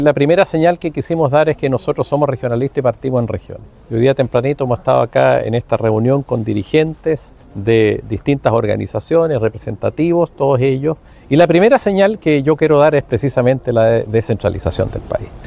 El abanderado de la Federación Regionalista Verde Social, Jaime Mulet, dio el puntapié inicial de su campaña en la región de O’Higgins, donde insistió en que foco el de su campaña estará en las regiones.